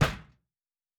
Metal Box Impact 1_4.wav